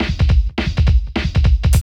14 LP FILL-L.wav